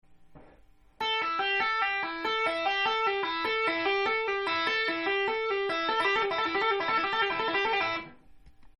Amキーで使える【カンタンライトハンド奏法フレーズ５選】エレキギターで上手そうに見せる速弾きアイデア
ライトハンド奏法フレーズ２
タッピングフレーズ２は２弦のAmペンタトニックスケールを使用しています。